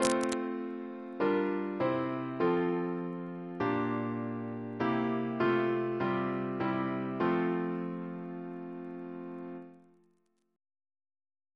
CCP: Chant sampler
Single chant in F Composer: Edward John Hopkins (1818-1901), Organist of the Temple Church Reference psalters: ACP: 205; PP/SNCB: 154